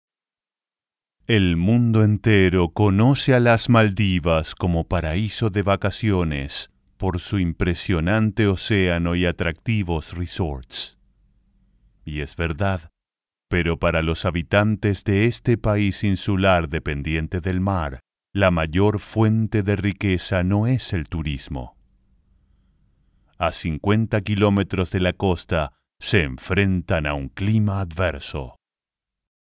locutor de español neutro